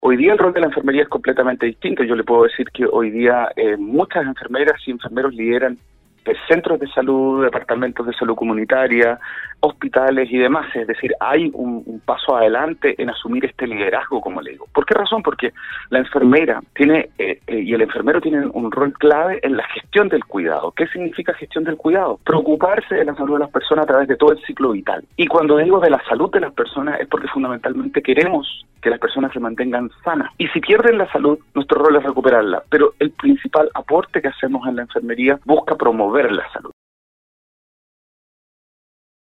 sostuvo un contacto telefónico en el programa Al Día de Nostálgica